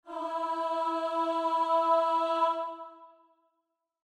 starting_note.mp3